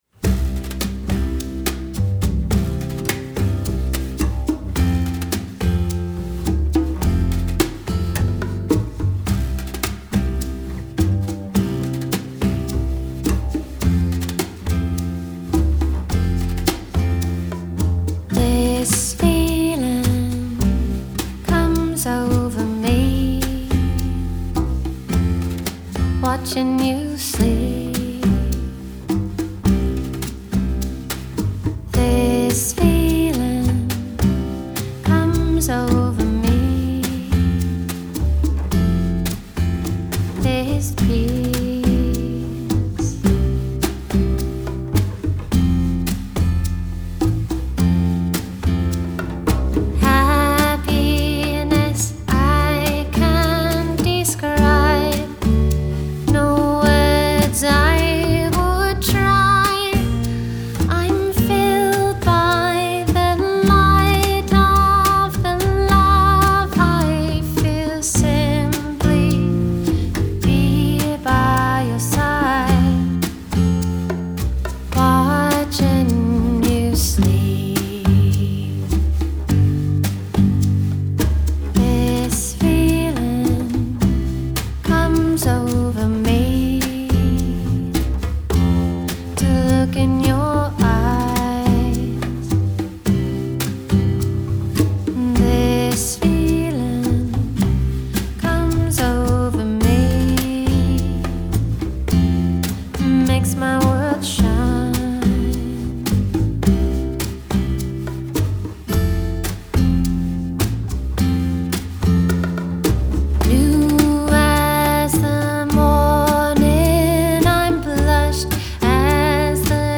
Alt. Country & Songwriter: